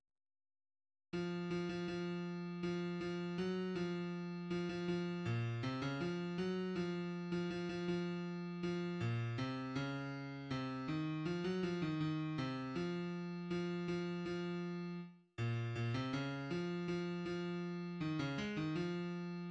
\clef bass \tempo 4=80 \key des \major \time 2/4